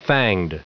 Prononciation du mot fanged en anglais (fichier audio)
Prononciation du mot : fanged